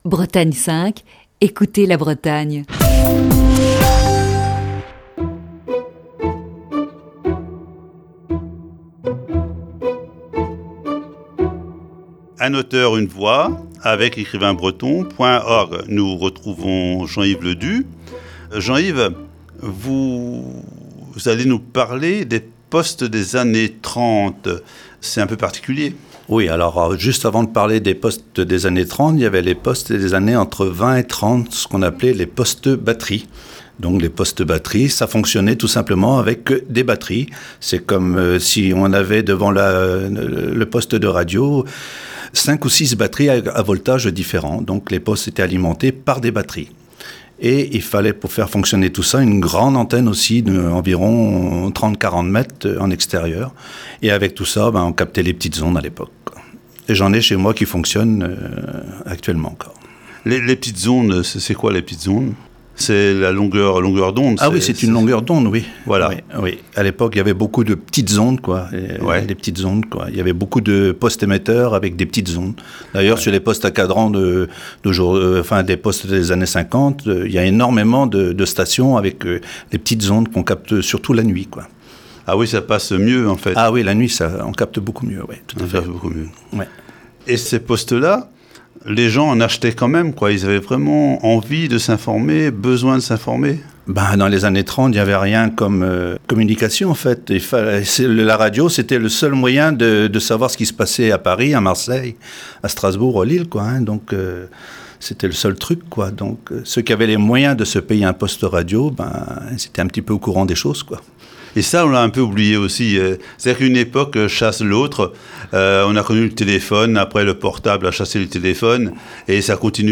Voici ce matin la deuxième partie de cette série d'entretiens.